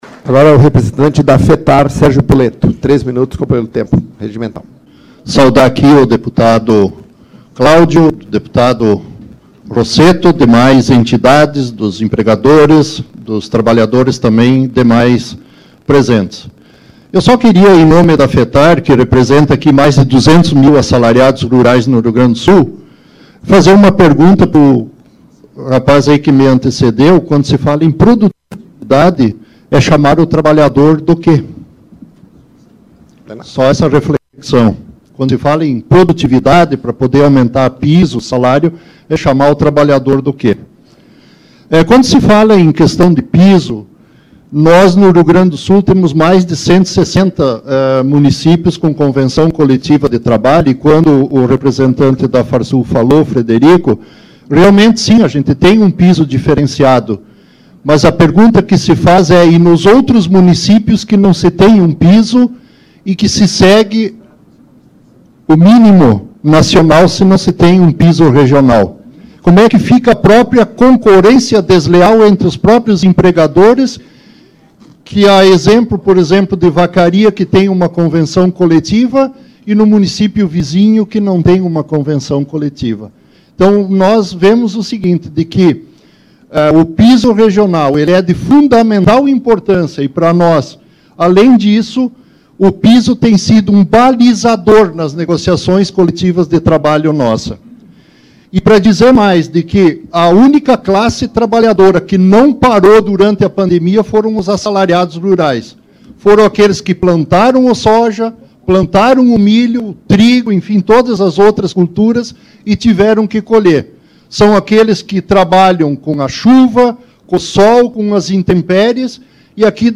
Audiência pública híbrida, no Plenarinho, da Comissão de Economia, Desenvolvimento Sustentável e do Turismo, para debater os impactos do piso regional na economia do Rio Grande do Sul.